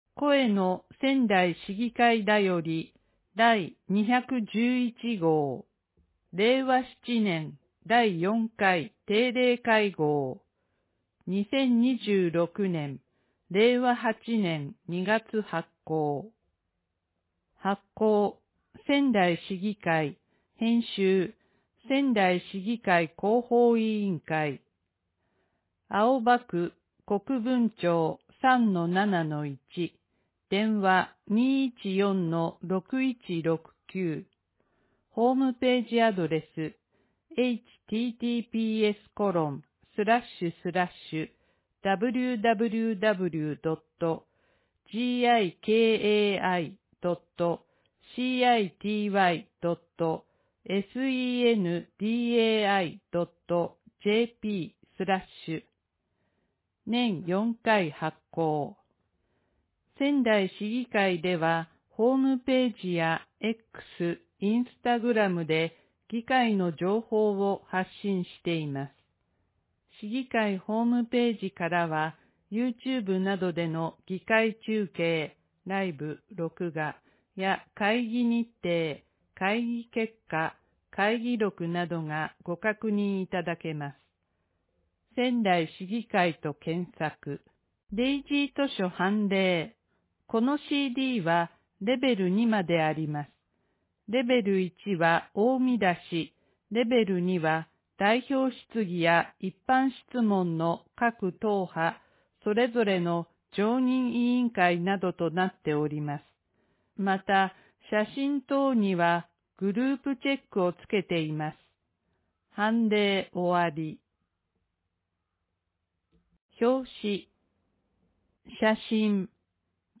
仙台市議会だより第211号（全ページ）（PDF：1,157KB） 仙台市議会だより第211号音声版（MP3：8.85MB） 1ページ 1ページ（PDF：428KB） TOPICS、年頭のごあいさつ、定例会会期日程、次回定例会のお知らせ、市議会からの情報発信 2・3ページ 2・3ページ（PDF：503KB） 代表質疑、常任委員会審議の概要、会派別賛否一覧表、用語解説、市議会SNSのご案内 4ページ 4ページ（PDF：397KB） 一般質問